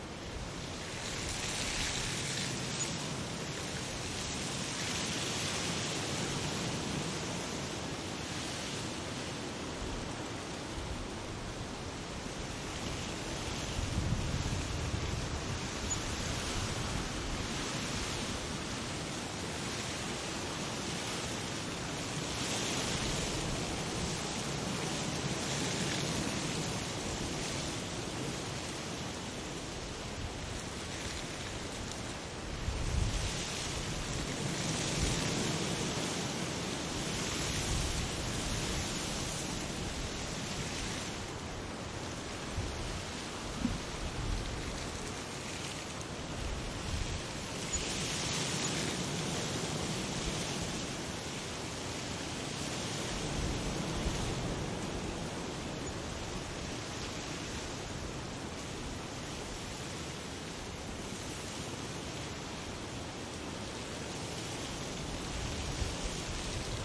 随机的 "风沉重的树木杨树哗哗作响的波浪，叶子大风的叶子
Tag: 阵风 海浪 白杨 叶子 叶子